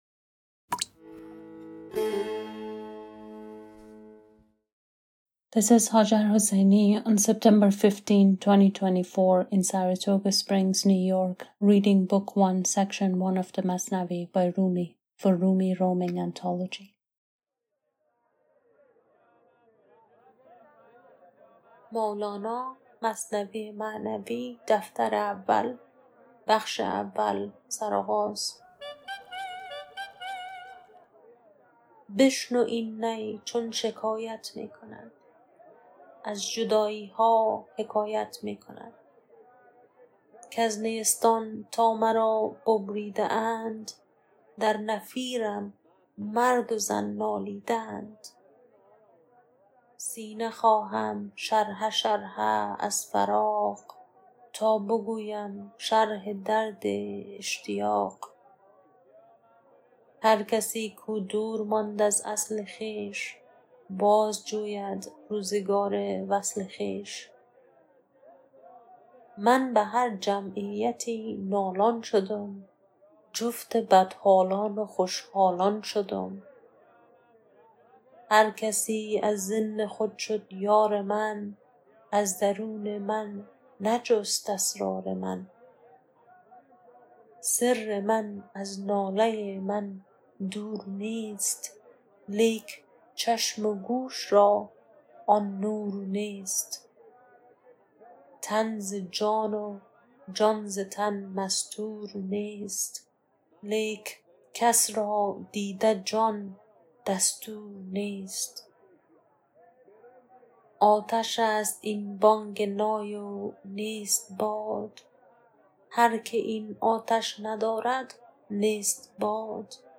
Book one, section 1 of the Masnavi by Rumi, read in Persian
Rumi, Masnavi, Translation, Rumi roaming, Poetry